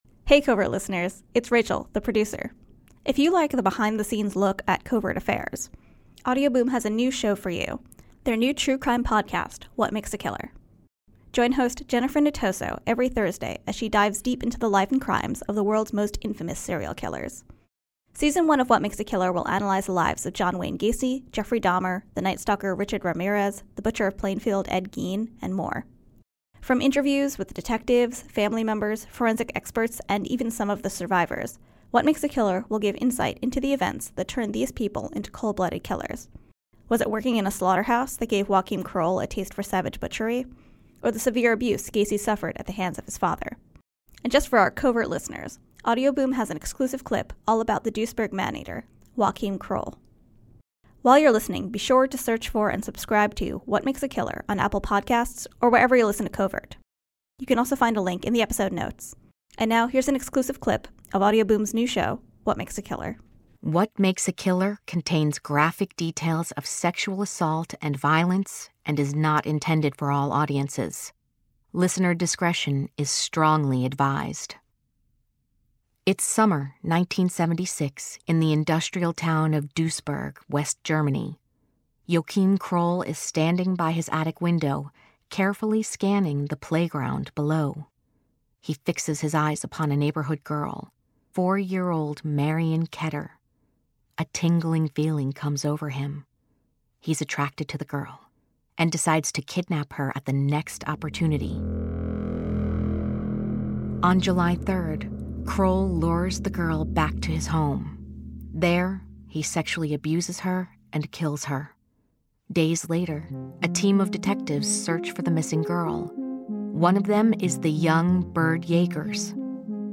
Listen to new episodes of What Makes a Killer for a look into the life and crimes of the world’s most infamous serial killers. Featuring interviews with family members, forensic experts, law enforcement, and witnesses.